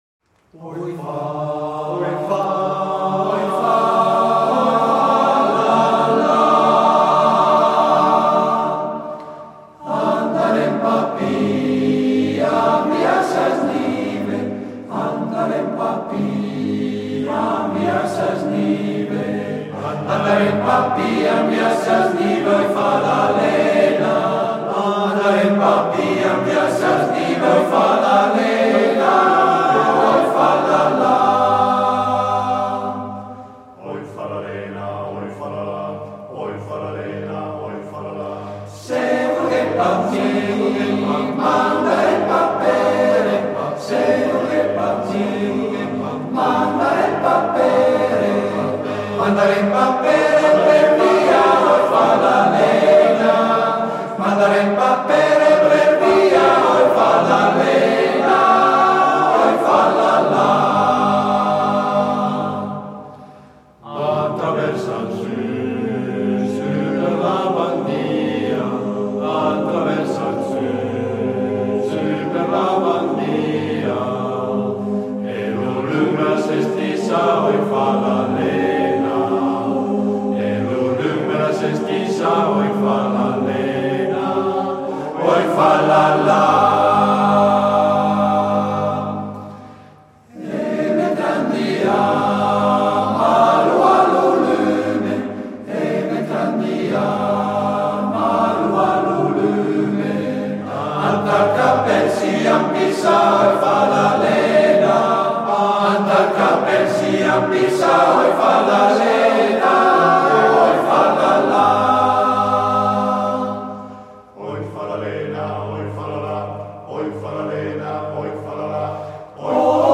Esecutore: Gruppo Corale "La Baita"